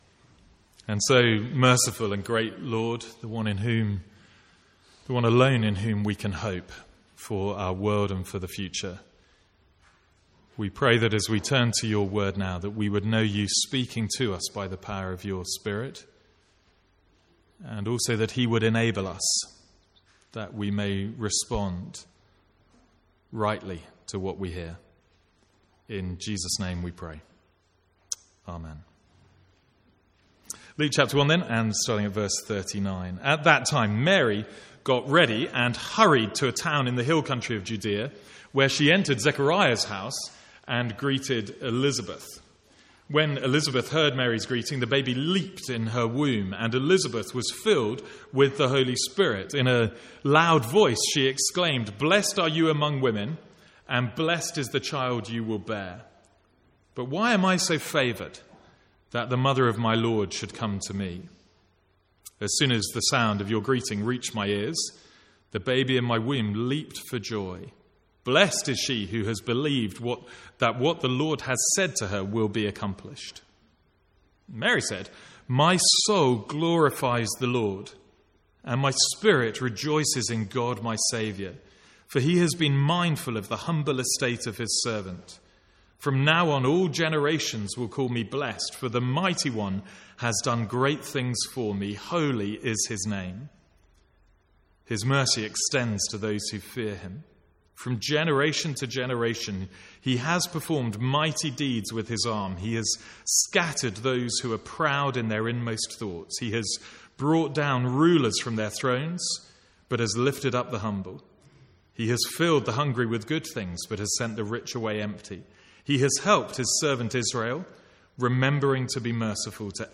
From the Sunday morning series in Luke.